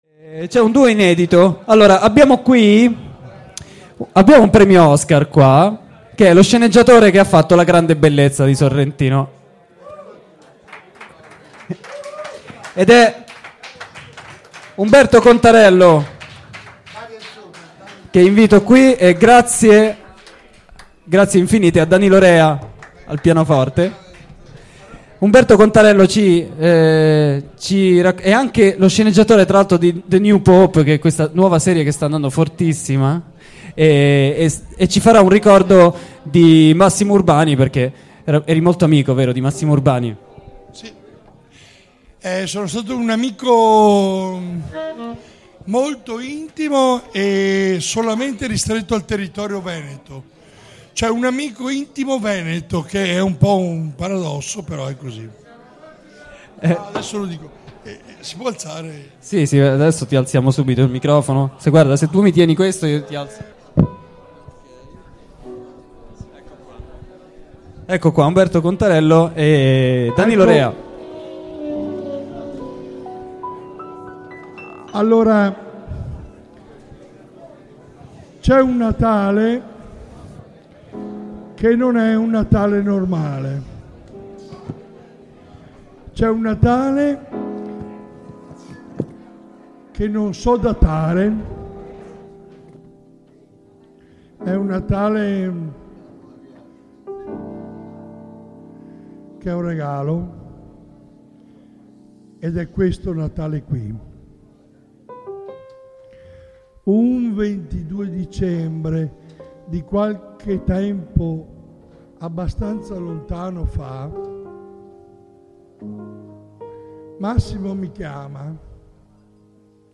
Monologo